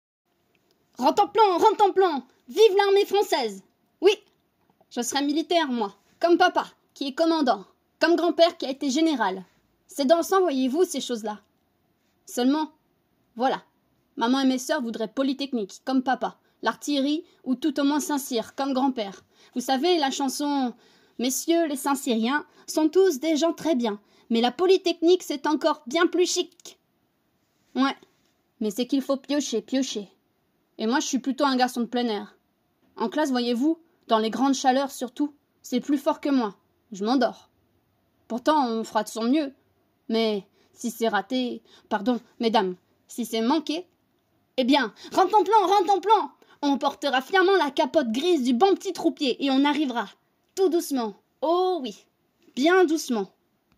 Voix Garçon - Soldat
8 - 37 ans